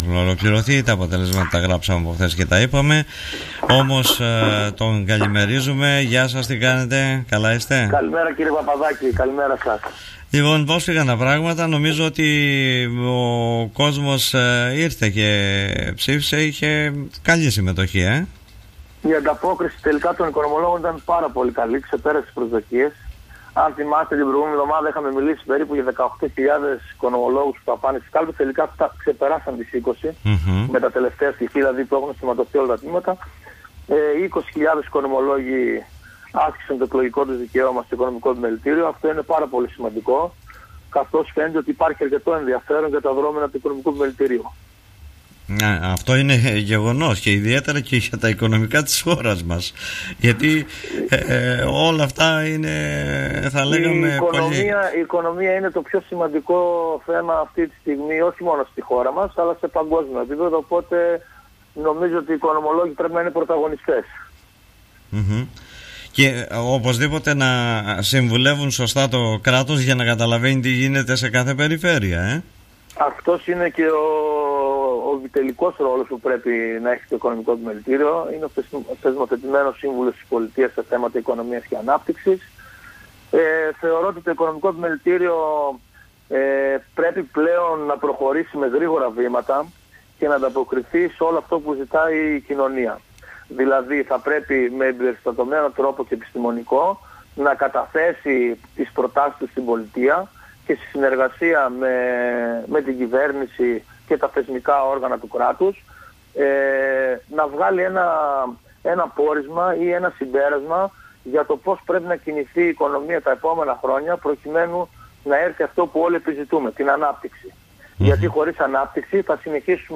μιλώντας στην εκπομπή